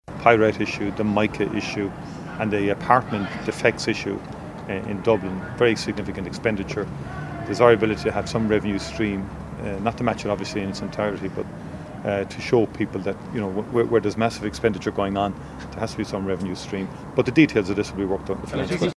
Taoiseach Micheál Martin is standing by the proposal announced as part of the Budget: